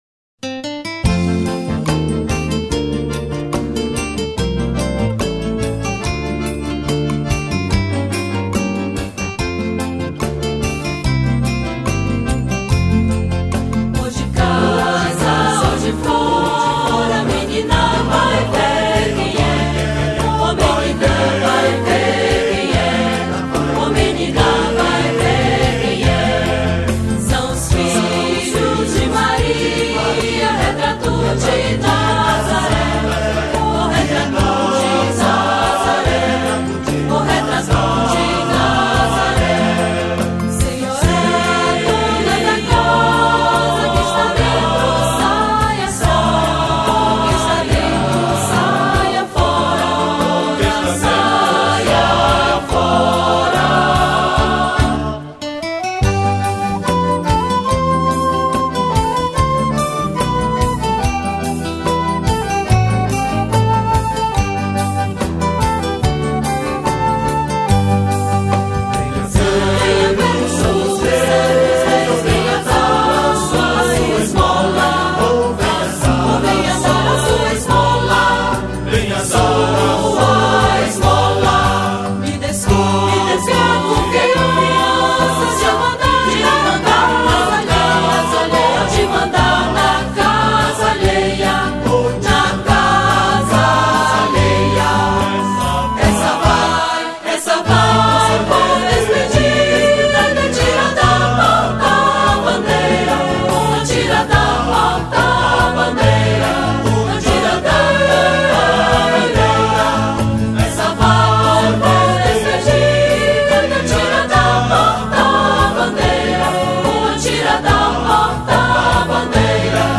Coral
Violao Acústico 6
Baixo Elétrico 6
Bateria
Flauta
Guitarra, Percussão, Cavaquinho, Teclados